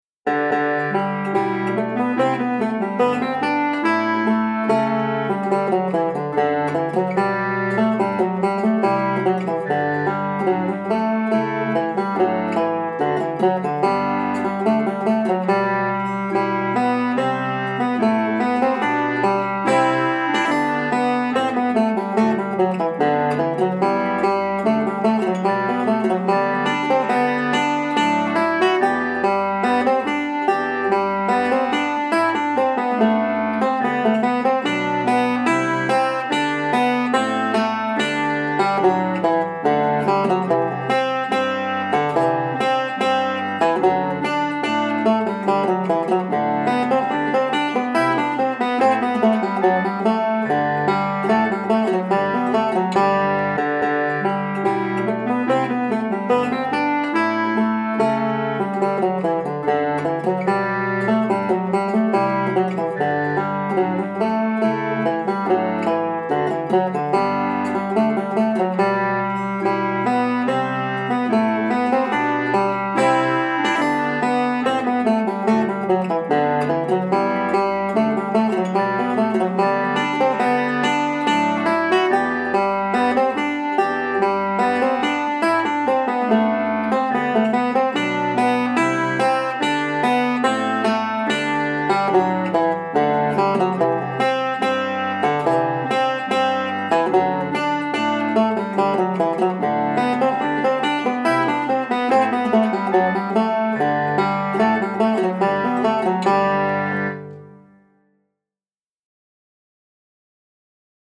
5-STRING BANJO
• Welch and Irish, Standard notation and Tab